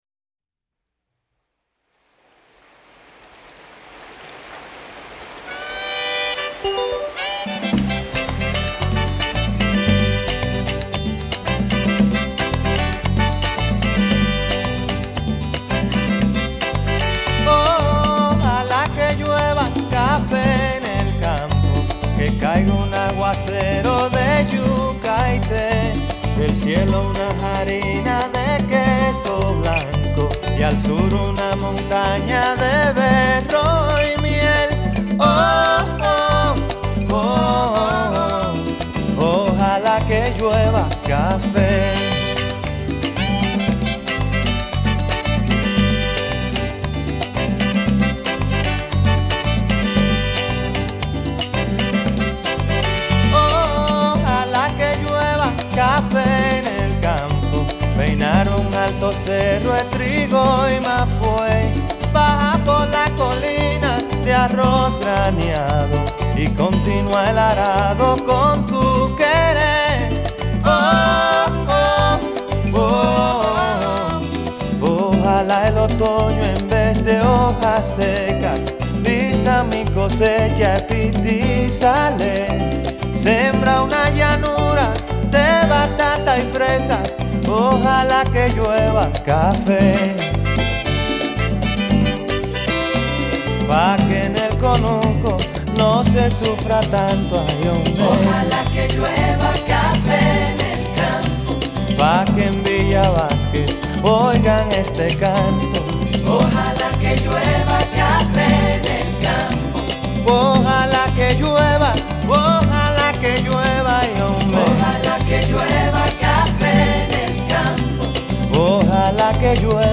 Canto
Música popular